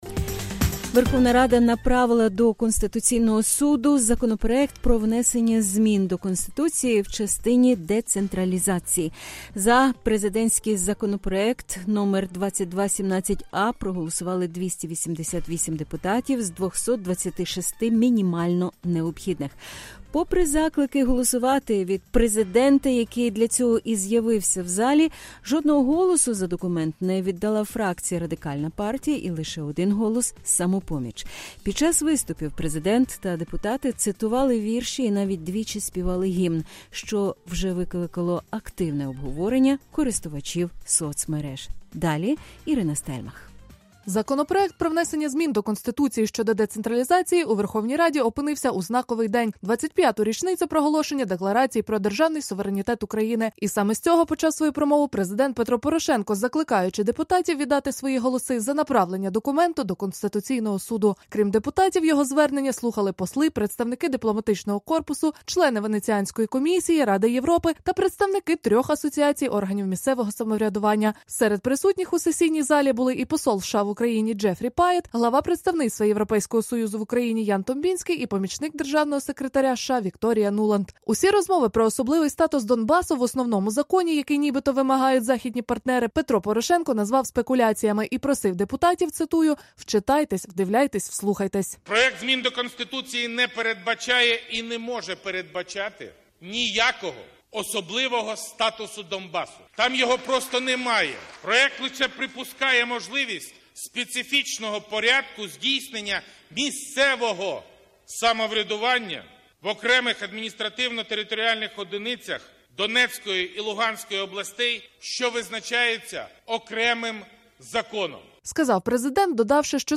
Лідер «Радикальної партії» Олег Ляшко (посередині) під час виступу у Верховній Раді. Київ, 16 липня 2015 року
Під час виступів президент та депутати цитували вірші і навіть двічі співали гімн, що вже викликало активне обговорення користувачів соцмереж.